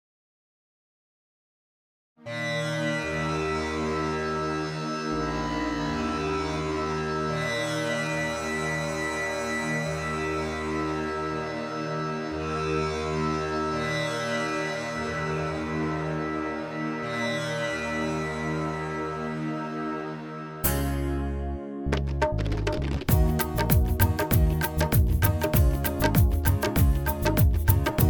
Karaoke Version Mp3 Track For Demo